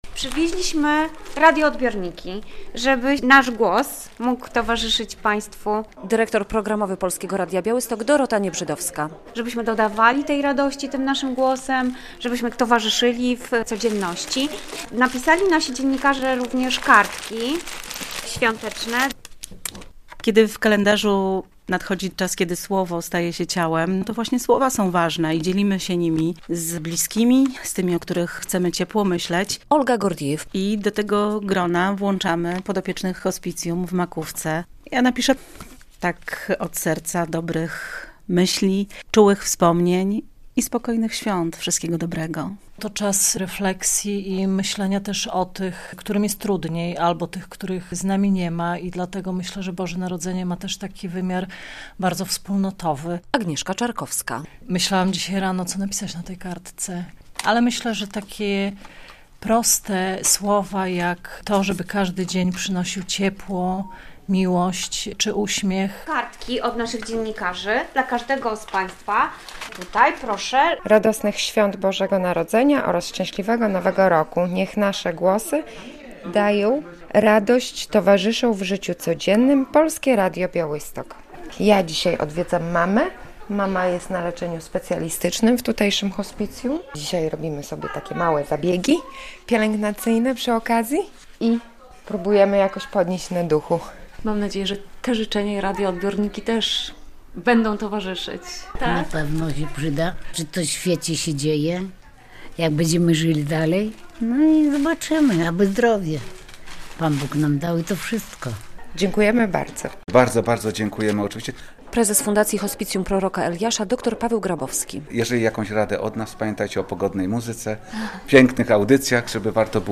Radioodbiorniki to nasz mikołajkowy prezent dla hospicjum w Makówce - relacja